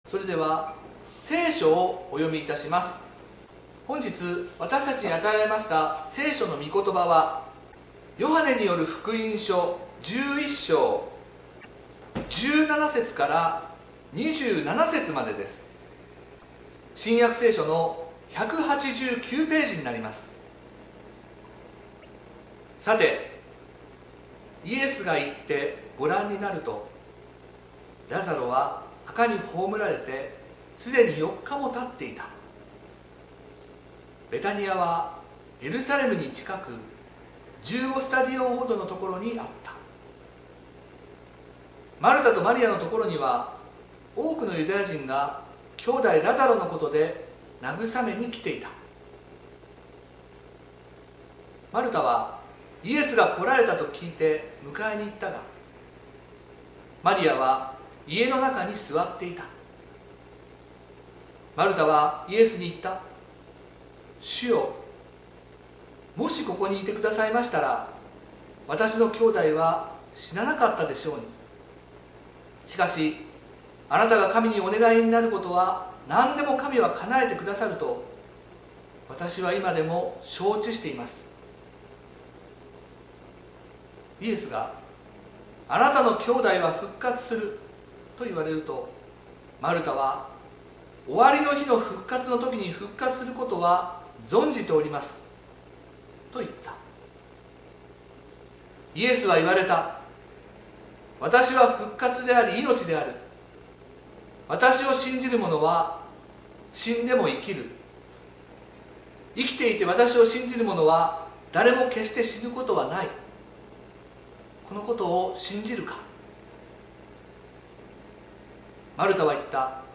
説教アーカイブ。